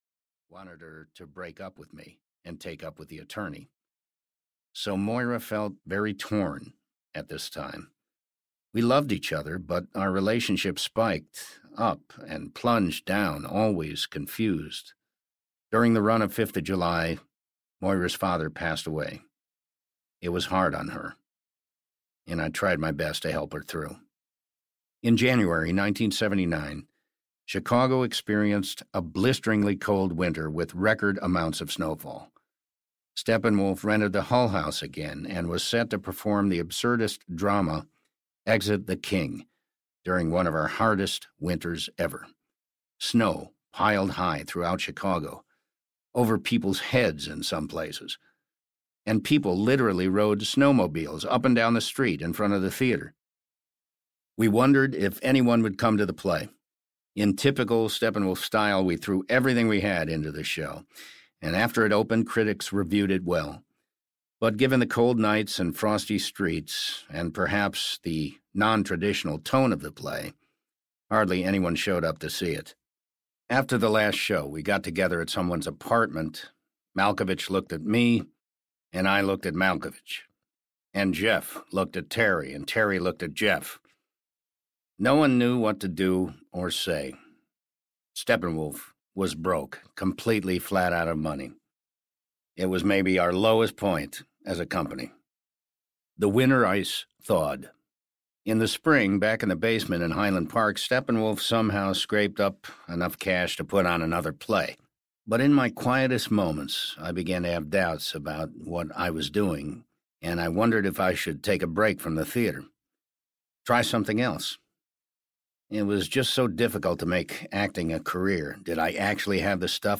12.22 Hrs. – Unabridged